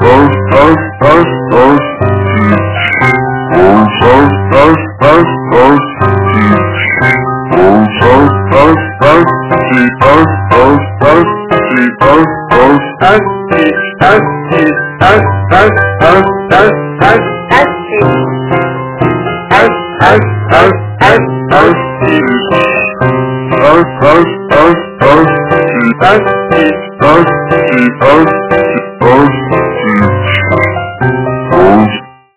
Bella-ciao-Black-MIDI-stick.mp3